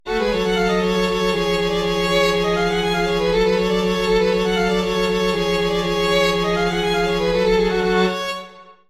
II Rondo Allegretto, mm.35-42